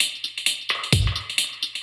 Index of /musicradar/dub-designer-samples/130bpm/Beats
DD_BeatC_130-01.wav